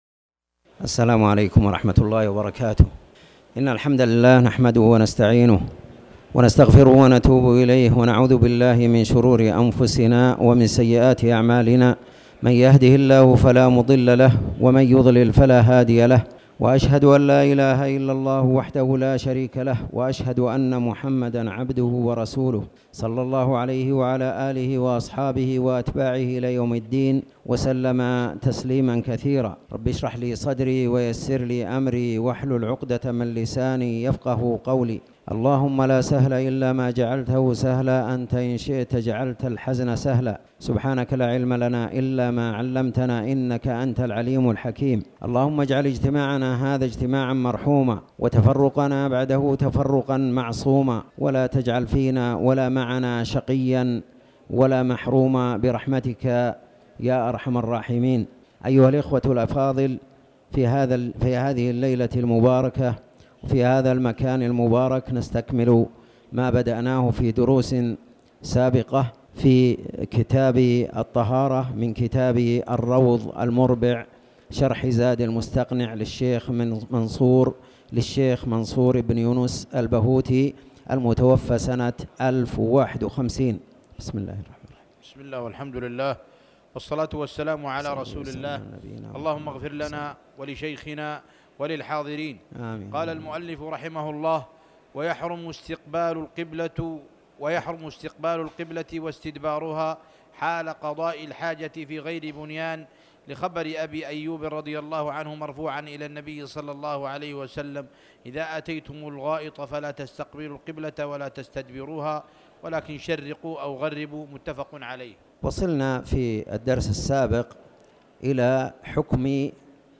تاريخ النشر ٣ جمادى الآخرة ١٤٣٩ هـ المكان: المسجد الحرام الشيخ